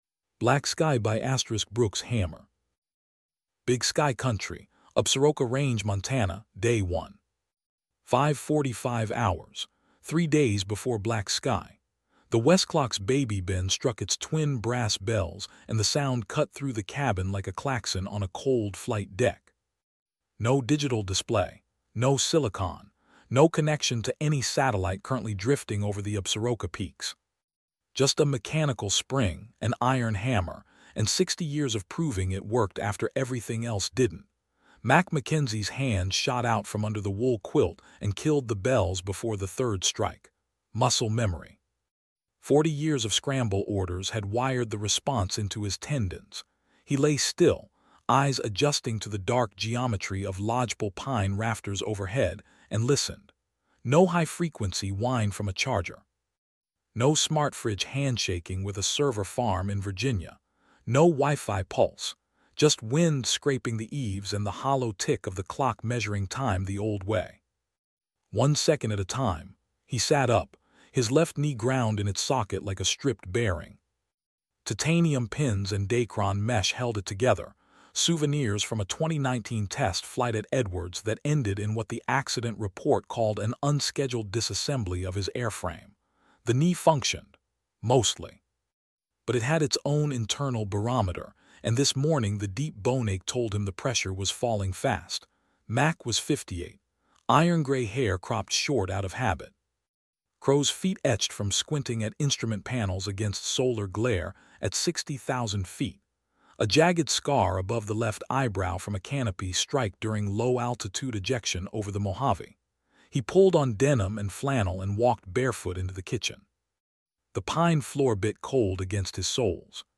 AUDIO BOOKS
Full-length audiobooks narrated in cinematic quality.